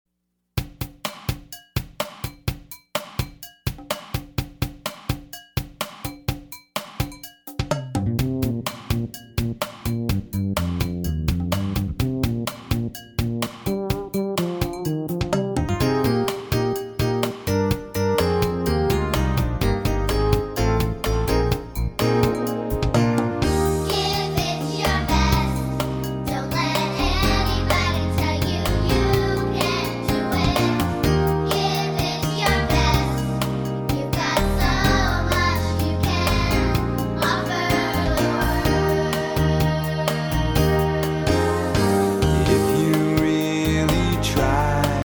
a children’s chorus